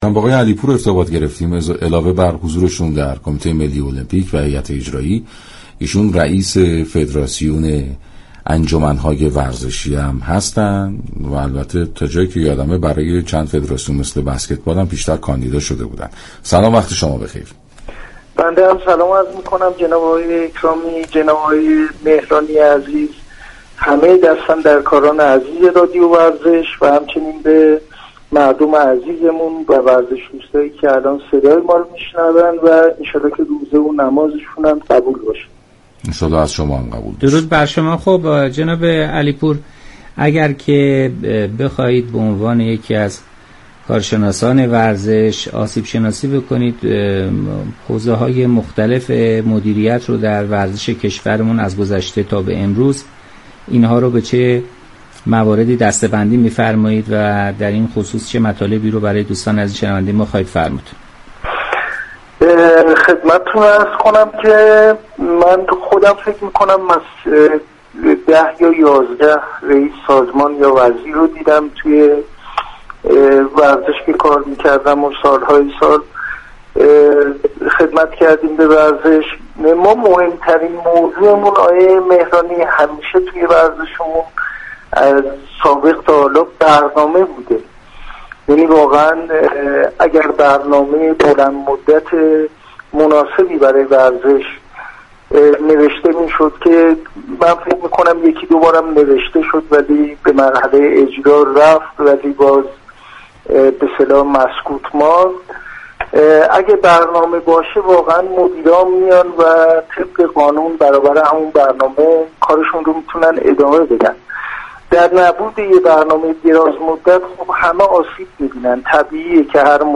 گفتگوی كامل